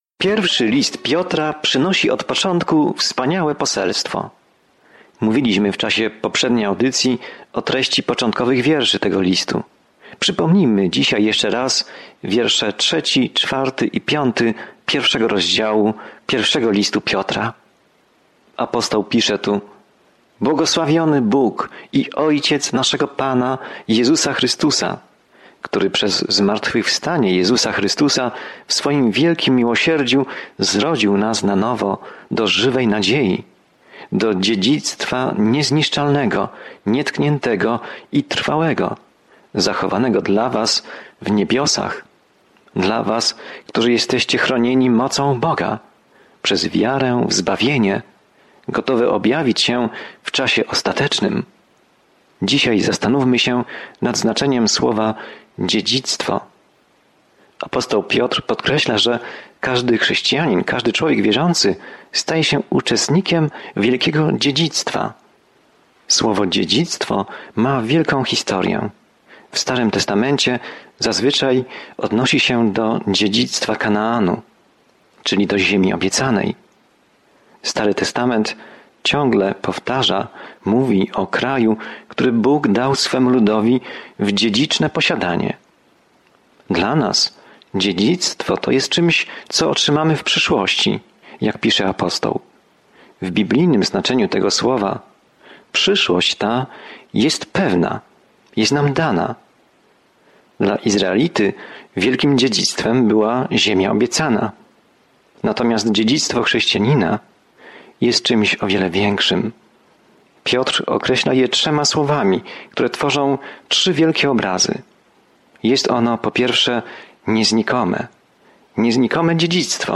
Pismo Święte 1 Piotra 1:5-9 Dzień 2 Rozpocznij ten plan Dzień 4 O tym planie Jeśli cierpisz dla Jezusa, ten pierwszy list Piotra zachęca cię, abyś podążał śladami Jezusa, który pierwszy cierpiał za nas. Codziennie podróżuj przez I List Piotra, słuchając studium audio i czytając wybrane wersety ze słowa Bożego.